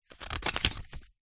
Звуки паспорта
Смотрят следующую страницу в паспорте